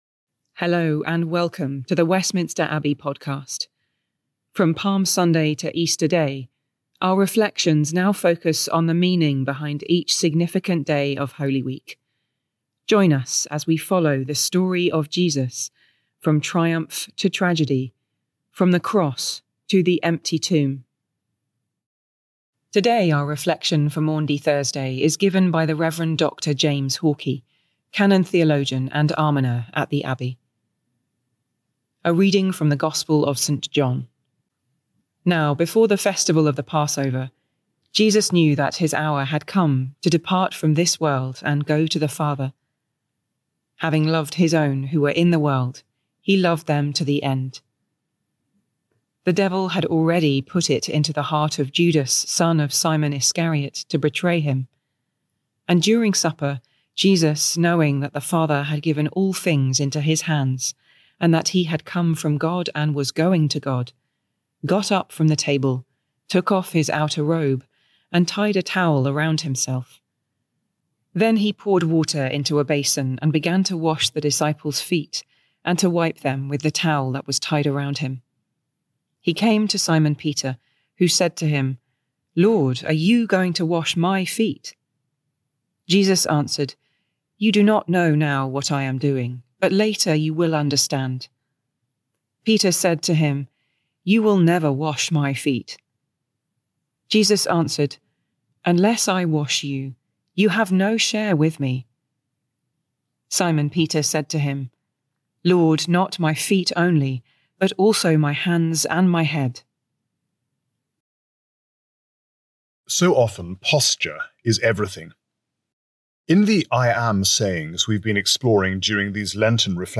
Westminster Abbey